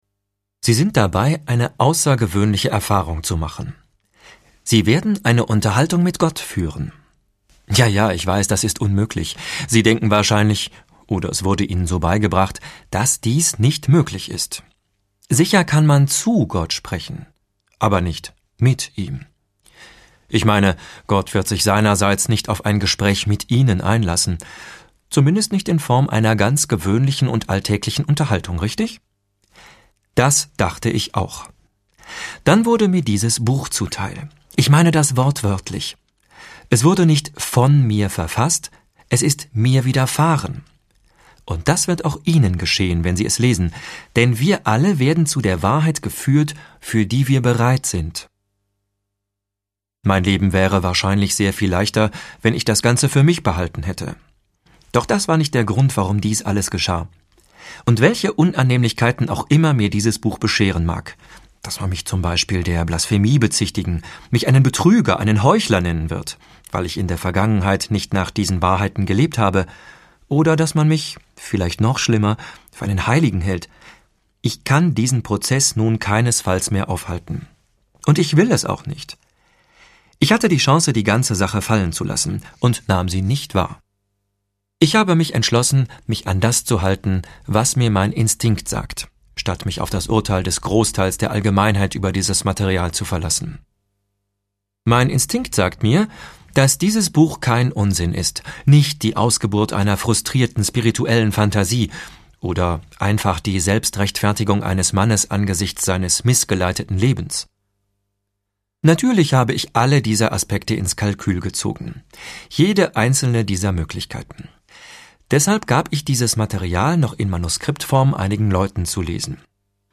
Der erste und grundlegende Band jetzt auch als mp3-Hörbuch!